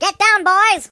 project_files/HedgewarsMobile/Audio/Sounds/voices/British/Takecover.ogg